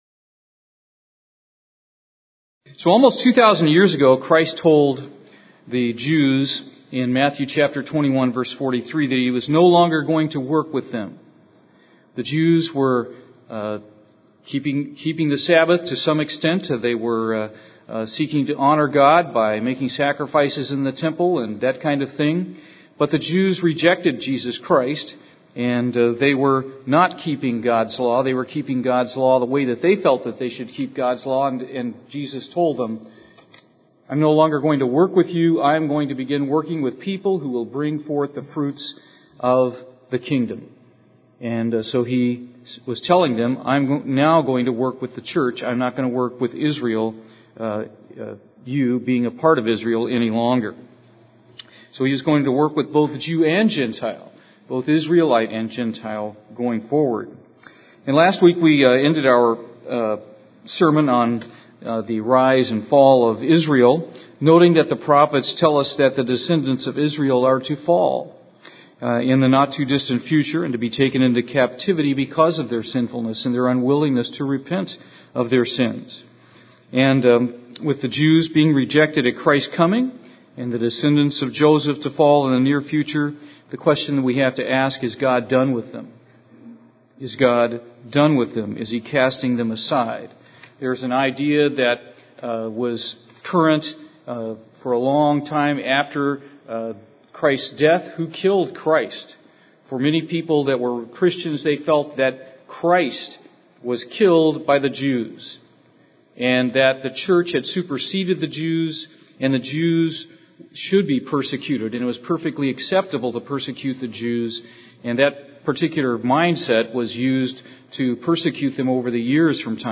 Given in Houston, TX
UCG Sermon Studying the bible?